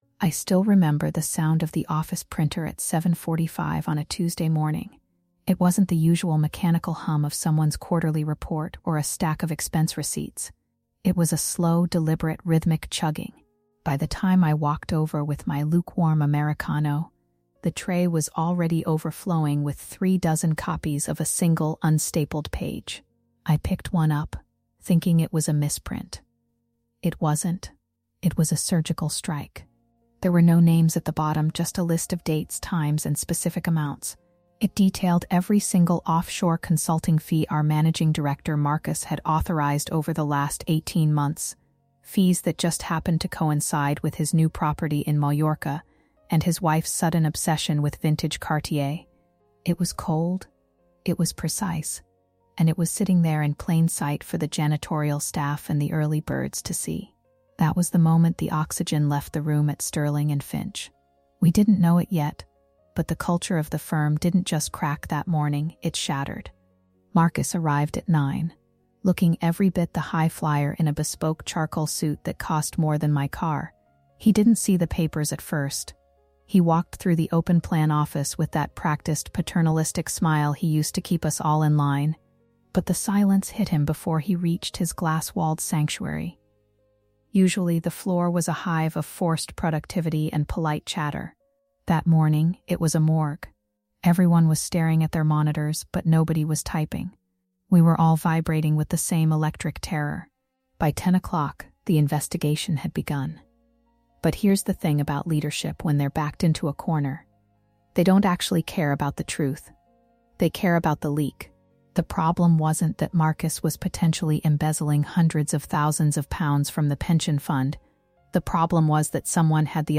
Follow our narrator, a direct witness to the fallout, as she recounts how a single sheet of paper detailing offshore embezzlement turned a professional sanctuary into a hunting ground.